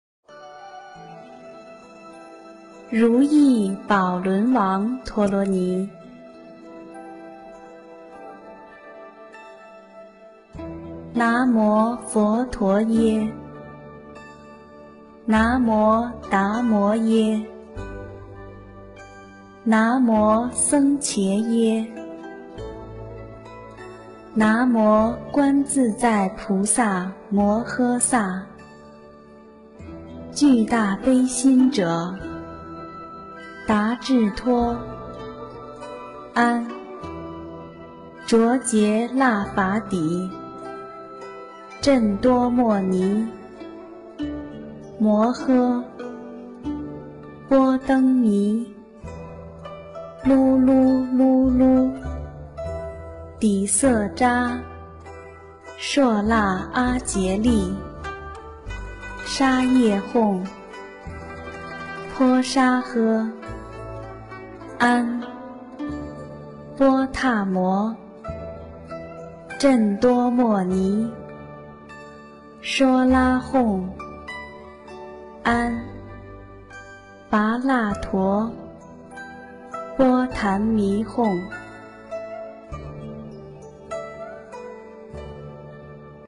《如意宝轮王陀罗尼》英文·最美大字拼音经文教念